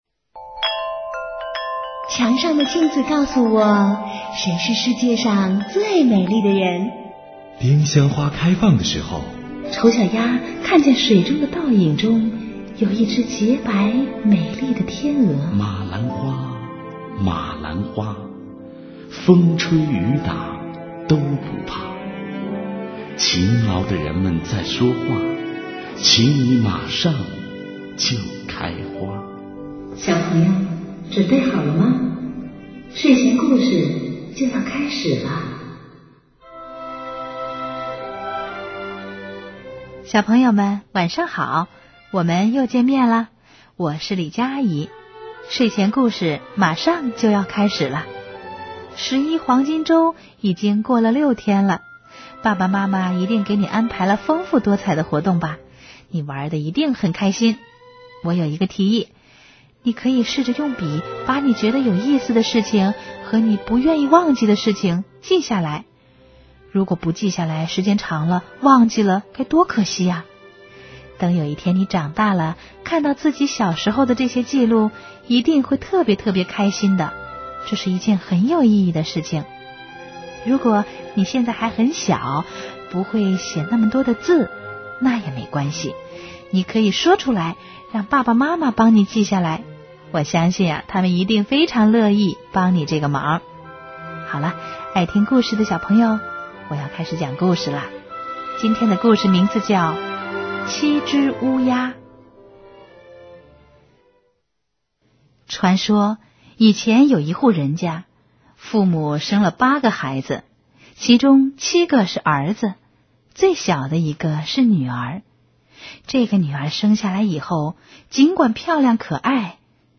睡前故事